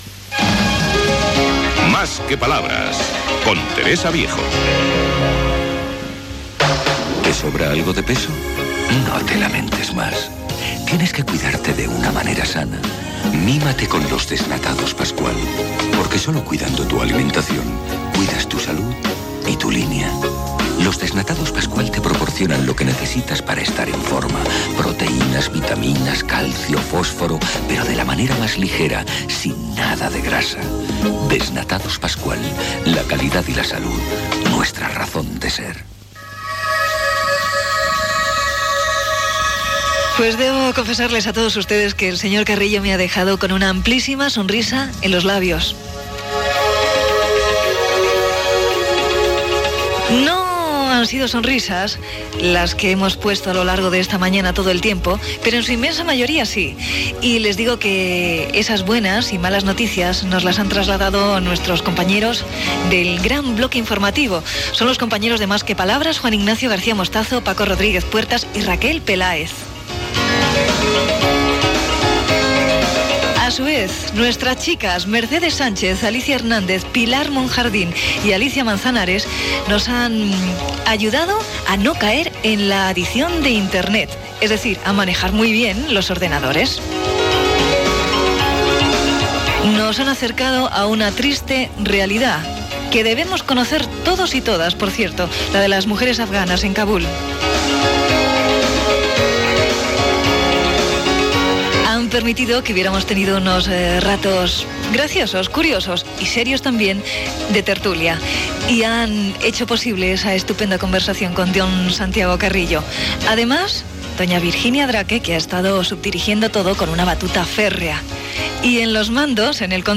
Indicatiu del programa, publicitat, equip del programa, temes que s'hi han tractat, comiat. Careta "Noticias". Talls a carreteres i línies de tren, vagues de transportistes a França, taula de l'oli d'oliva, etc Espai publicitari presentat per Ramón Sánchez Ocaña.
Informatiu
FM